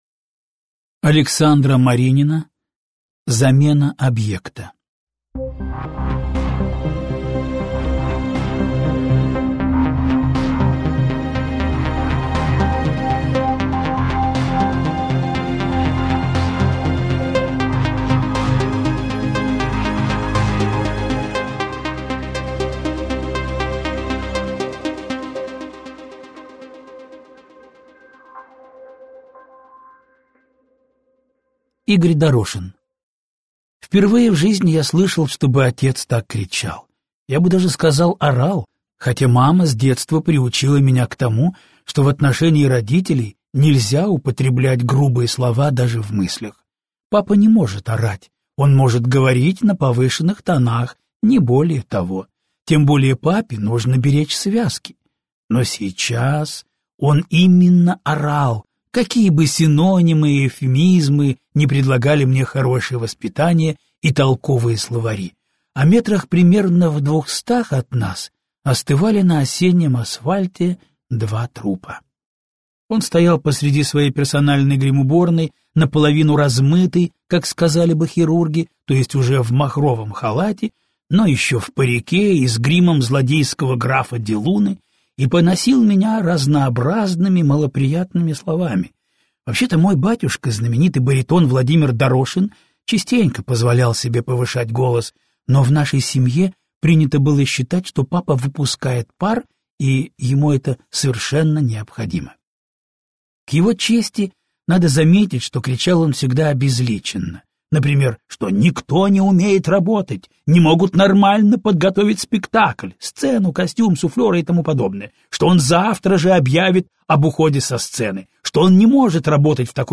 Аудиокнига Замена объекта | Библиотека аудиокниг